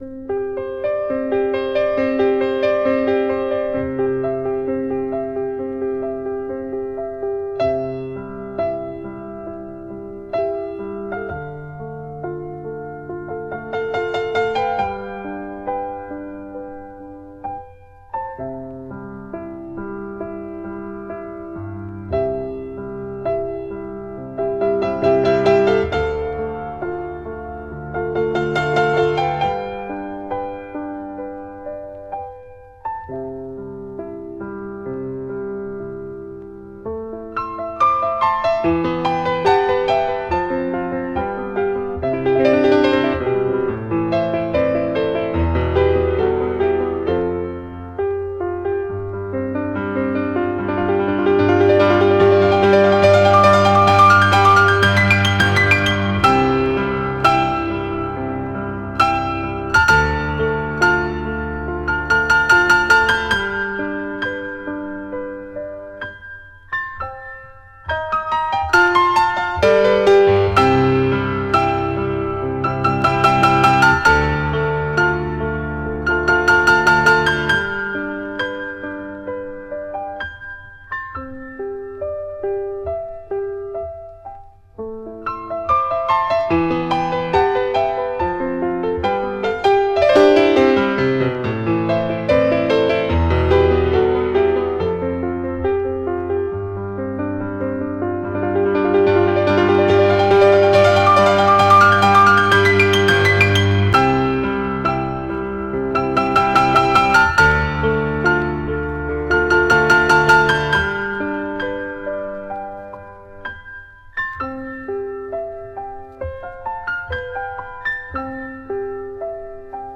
BPM20-78
Audio QualityMusic Cut